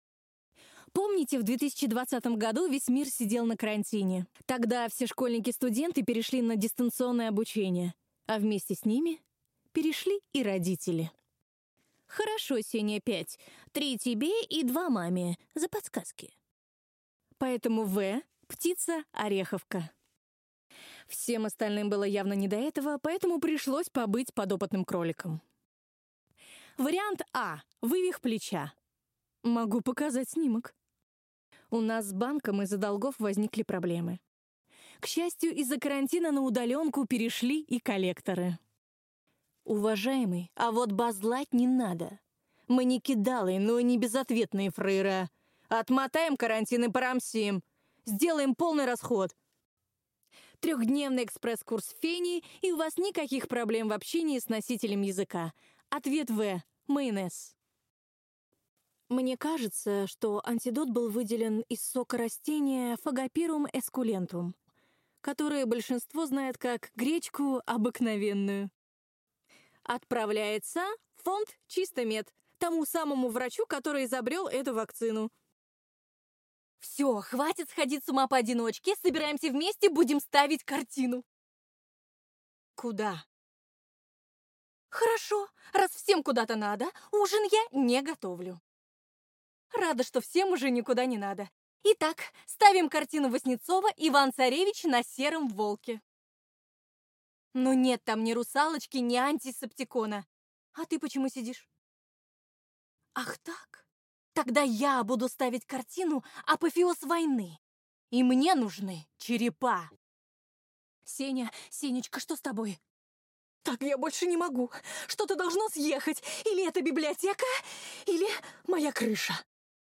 demo ozvuchanie
voice dry
Женский